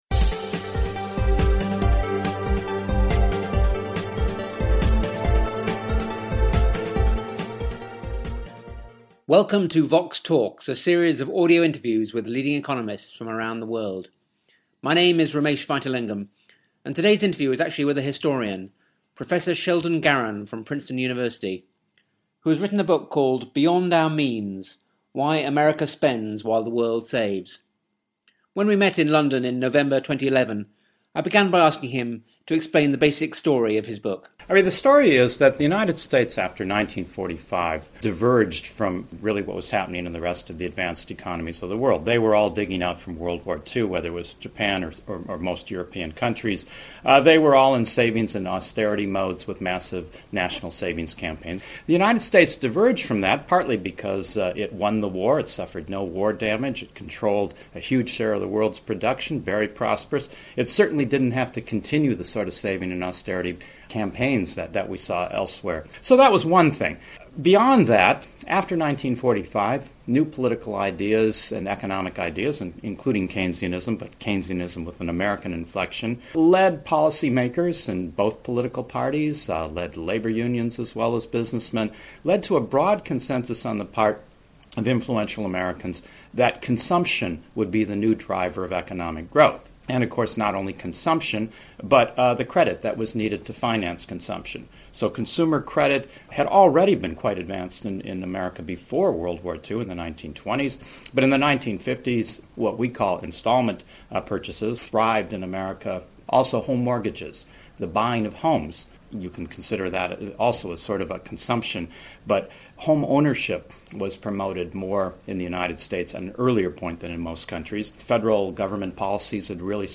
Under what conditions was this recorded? He contrasts continental European and East Asian countries, which have over many decades encouraged their citizens to save, with the US, which has promoted mass consumption and reliance on credit, culminating in the global financial meltdown. The interview was recorded in London in November 2011.